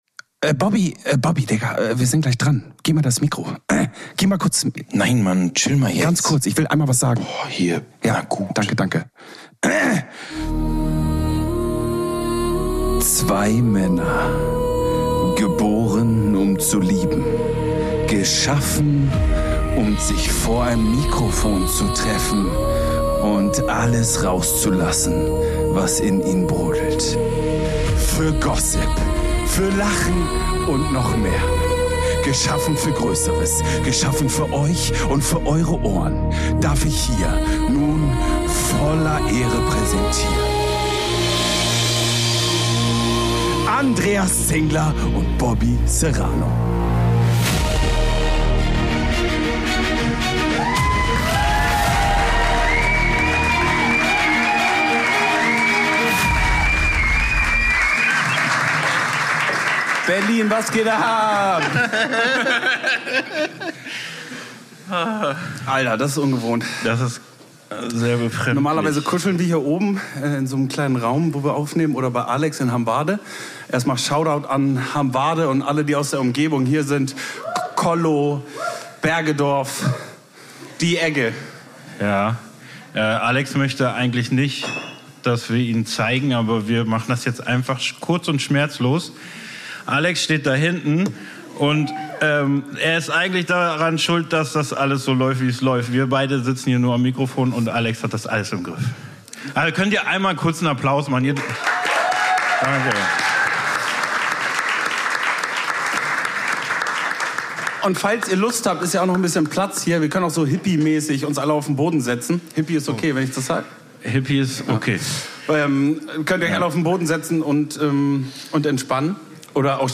UNSERE ERSTE LIVE FOLGE